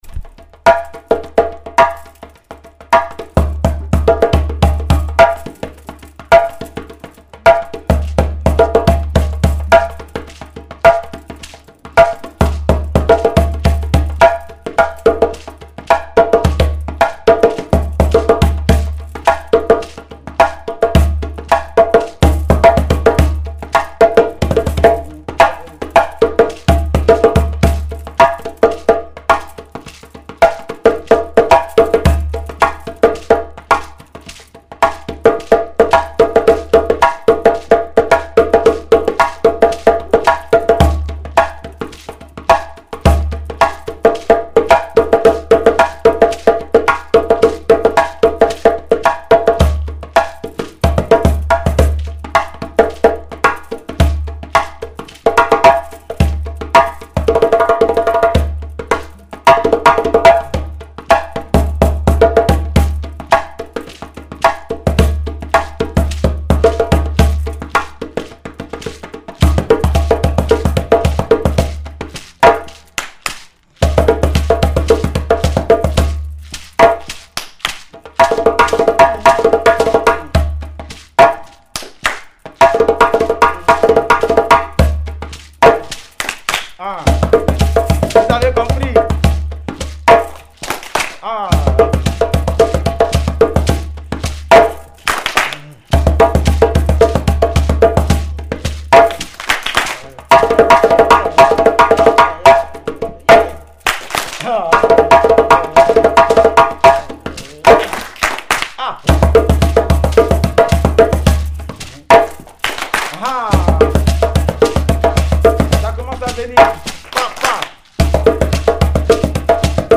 chanteur et percussionniste
solo de Djembé